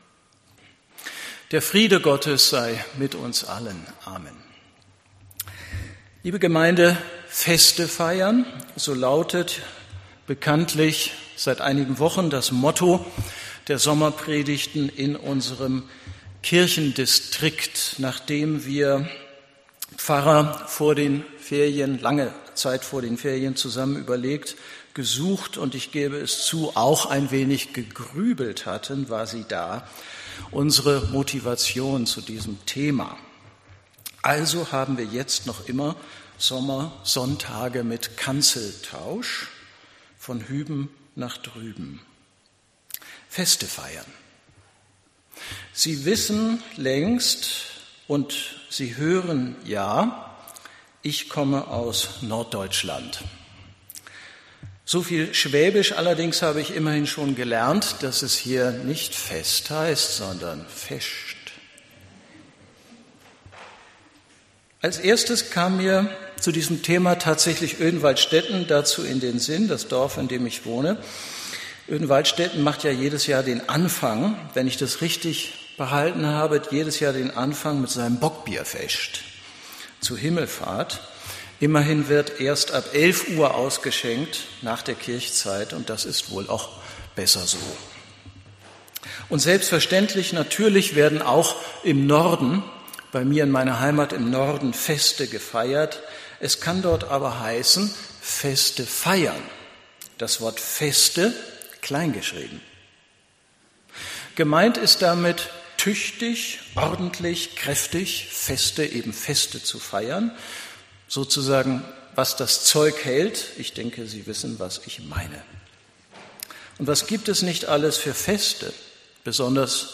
Predigt in der Sommerpredigtreihe „Feste feiern“